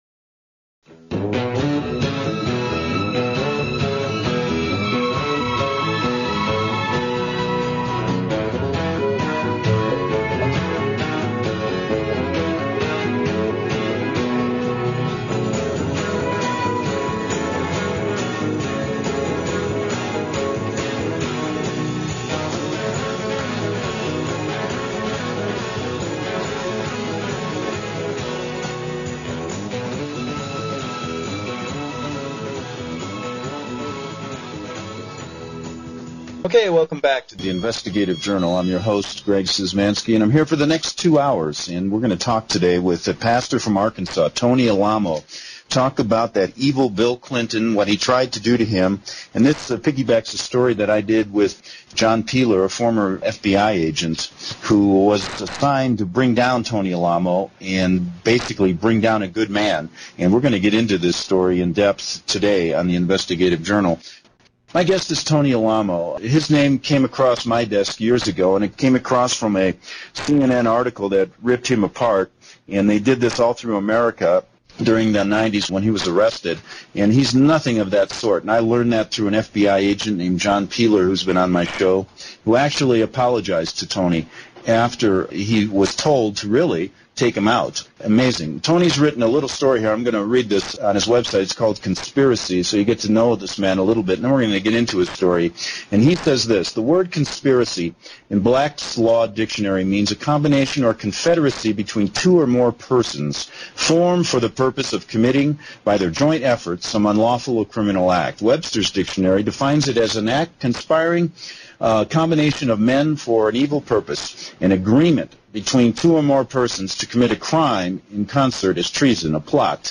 Talk Show Episode
Pastor Alamo speaks of his powerful salvation testimony and many of the supernatural experiences he has had. He also tells of some of the persecution the church has endured over the last 50 years. This is a wonderful interview.